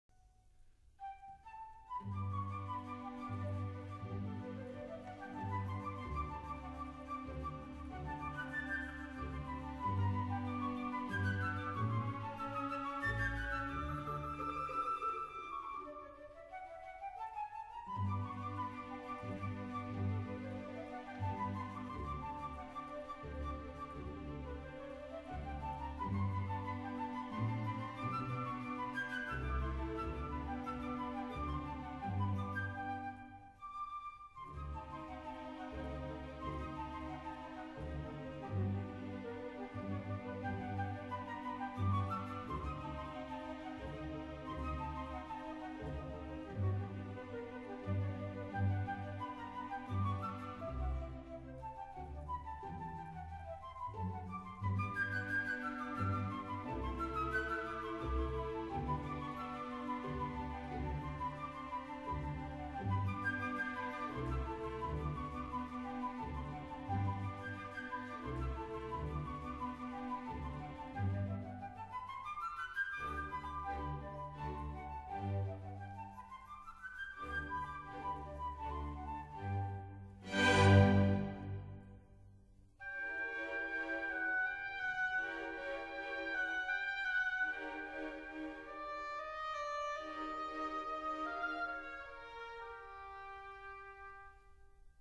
Allegro moderato
快板 <01:35>
Sorry, 论坛限制只许上传10兆一下，而且现在激动的网速巨慢，所以只能牺牲品质，为大家介绍一下音乐了。